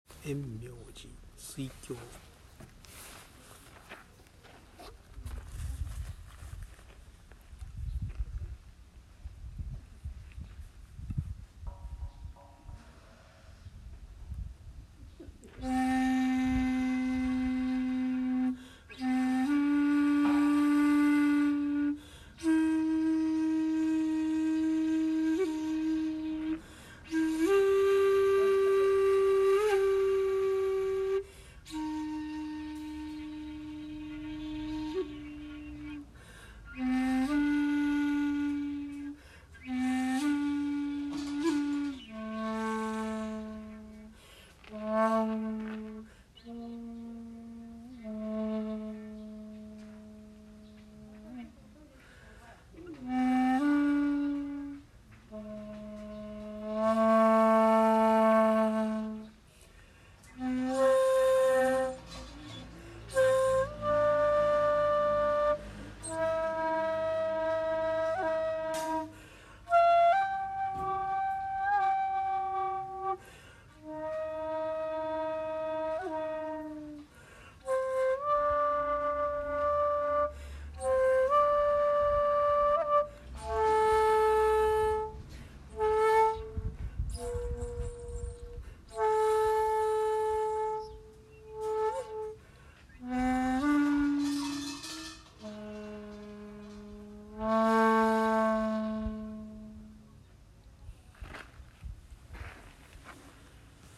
納経して旅の安全を祈念して尺八を吹奏しました。
（写真⑤：円明寺にて尺八吹奏）
◆　（尺八音源：円明寺「水鏡」）